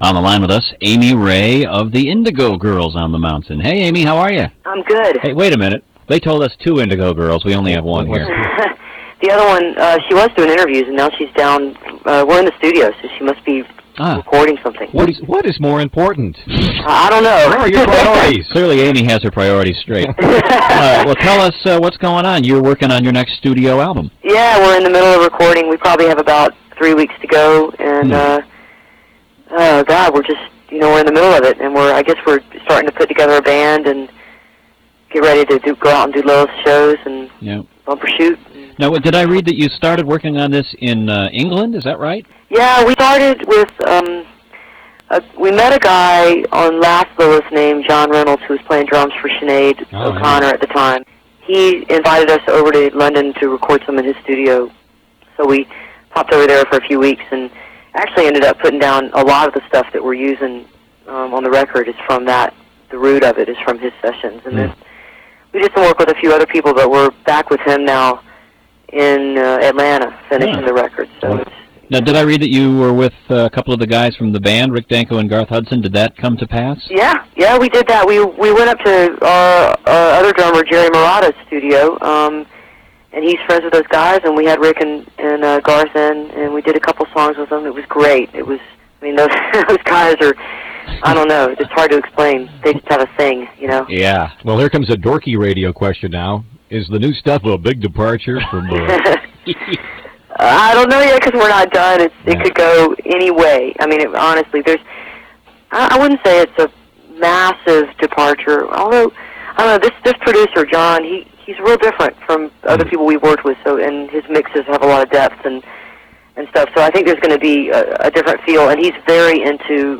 01. interview with amy (live on kmtt 6/1/99) (7:41)
tracks 1-4 live on kmtt - seattle, washington